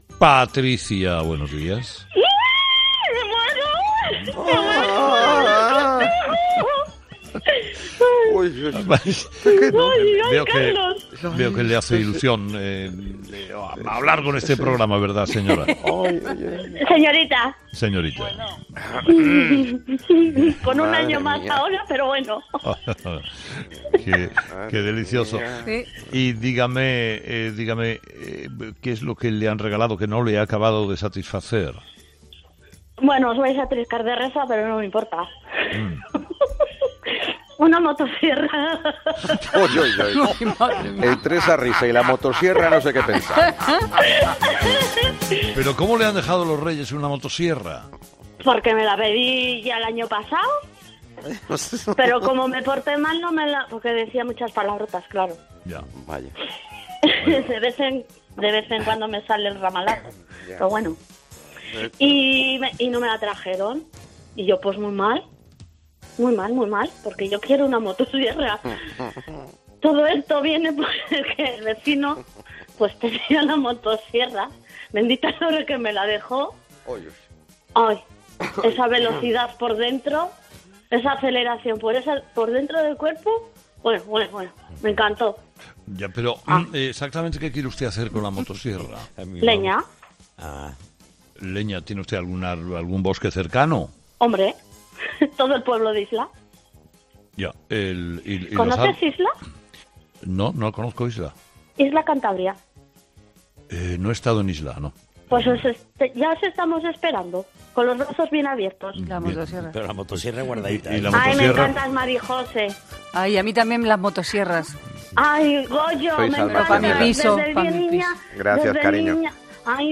El éxtasis de una 'fósfora' al hablar con Carlos Herrera en directo
'Los Fósforos' es el espacio en el que Carlos Herrera habla de tú a tú con los ciudadanos, en busca de experiencias de vida y anécdotas deliciosas, que confirman el buen humor y cercanía de los españoles.